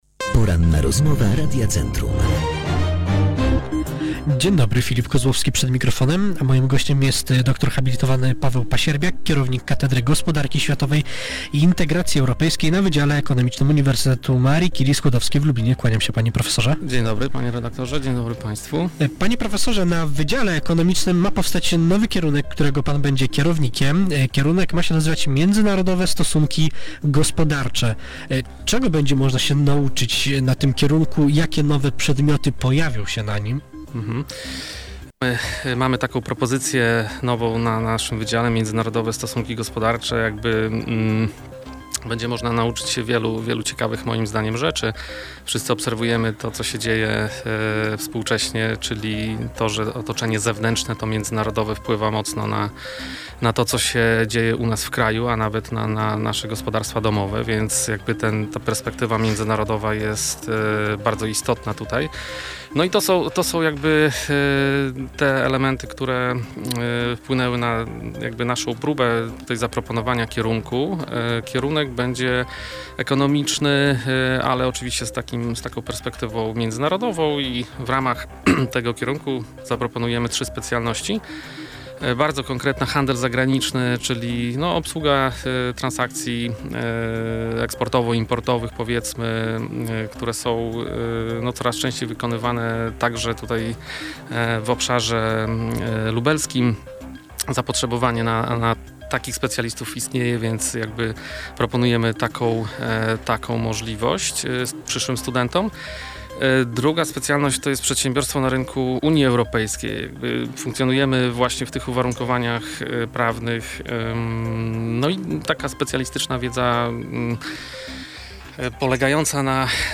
W porannej rozmowie poruszone zostały również obecne międzynarodowe stosunki gospodarcze. Co nasz rozmówca sądzi o płaceniu w rublach za gaz oraz o Krajowym Planie Odbudowy?